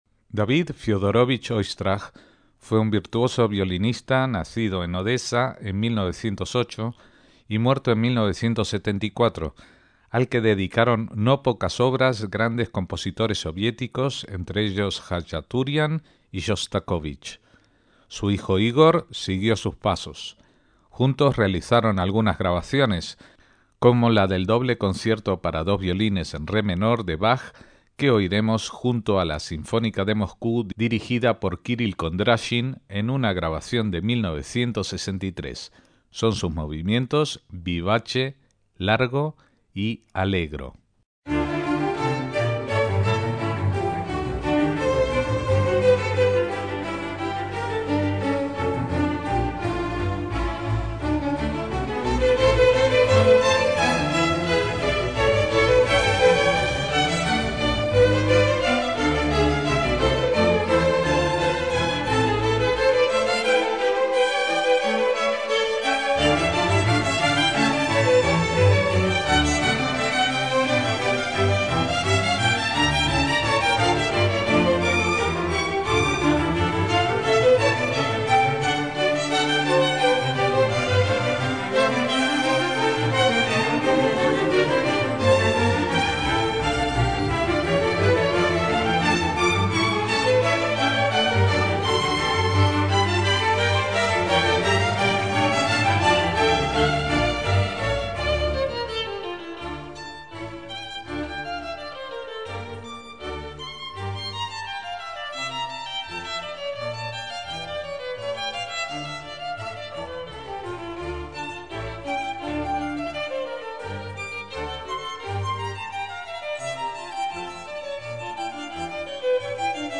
David Oistrakh y su hijo Igor tocan el doble concierto de Bach
MÚSICA CLÁSICA